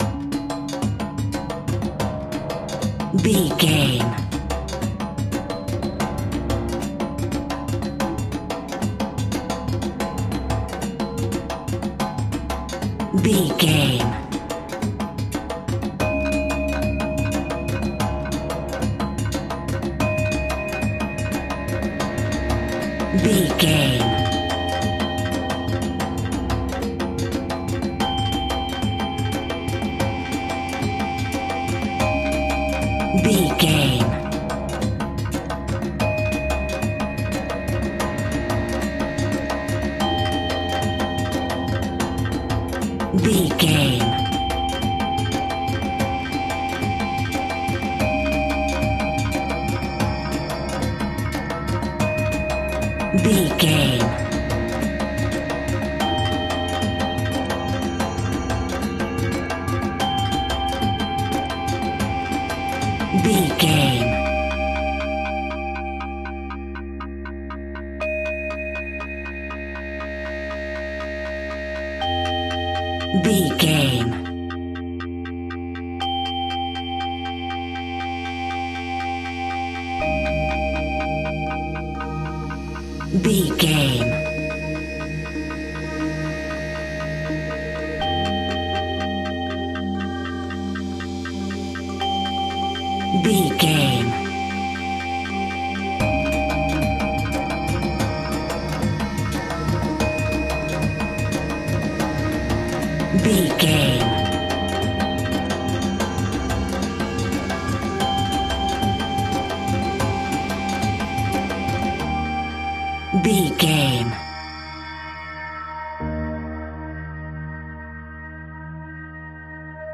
In-crescendo
Thriller
E♭
tension
ominous
haunting
eerie
percussion
synthesiser
piano
ambience
pads